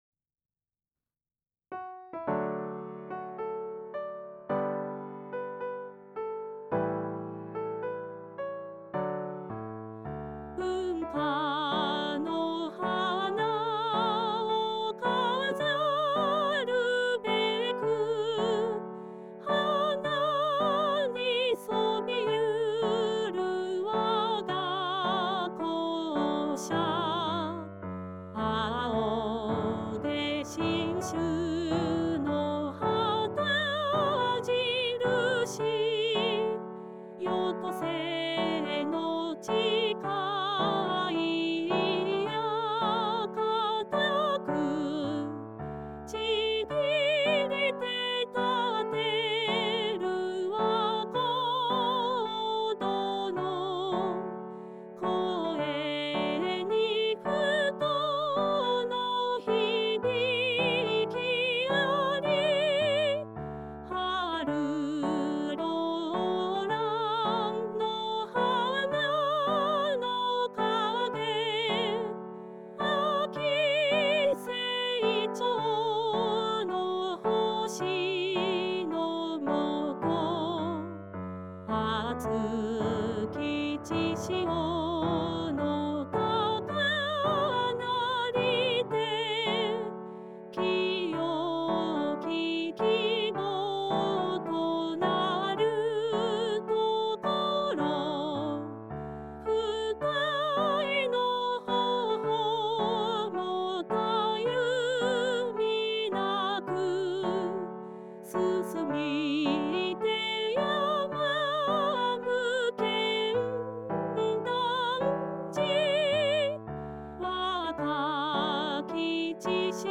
電影会歌（合成歌唱付き）のダウンロード (2014年5月改定)